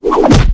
combat / dragons / bash2.wav
bash2.wav